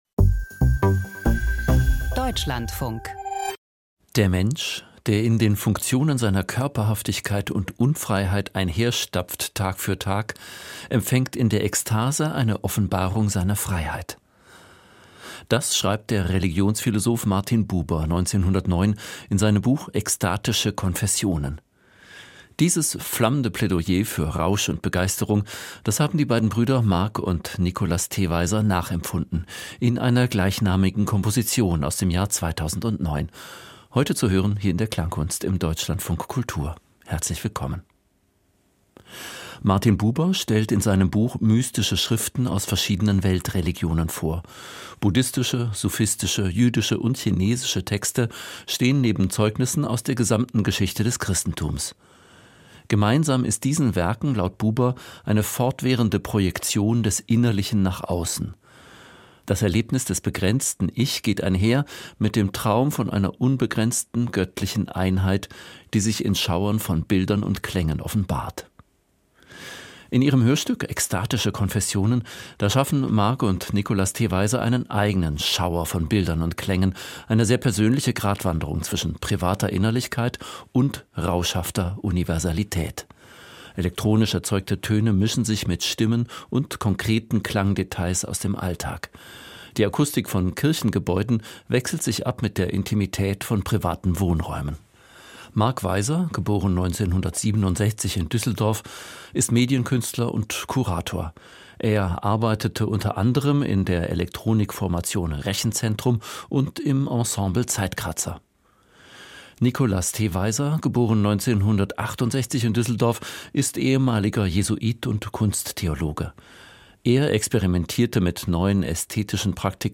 Hörspielcollage über den Kult um die Hollywood-Heldin, über Aufstieg, Fall, Tragik und Glanz – über Marilyn Monroe, Mythos und Opfer der Kulturindustrie.